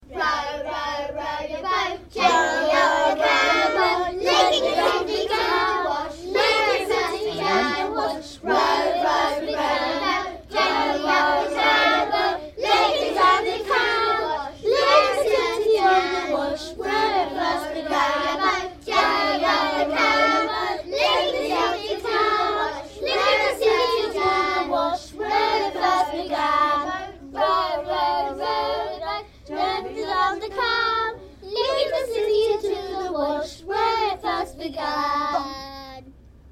To The Wash (Round)
Lyrics by CBBC Song writer Dave Cohen To the tune of popular nursery rhyme Row Your Boat Sung by Abbey Meadows Choir